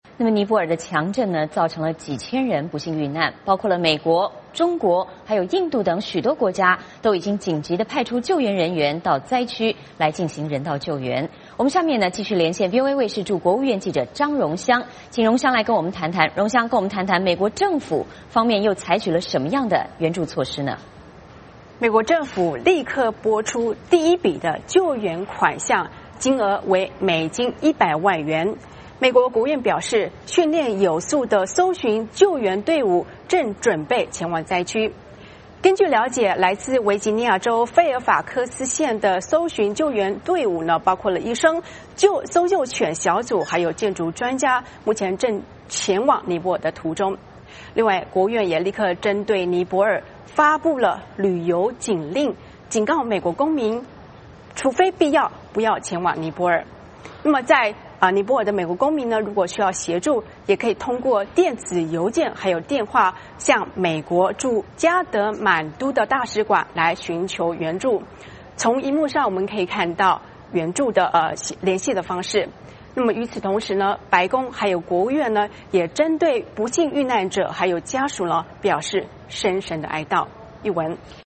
请她介绍相关情况。